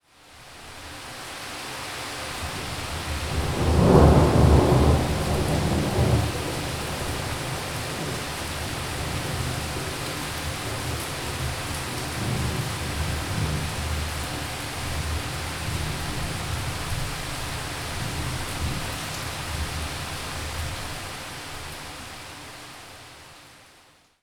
• mountain thunder roll with heavy rain.wav
A storm recorded in Southern Carpathian Mountains. Recorded with Tascam DR 40
mountain_thunder_roll_with_heavy_rain_Ijk.wav